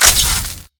damaged.ogg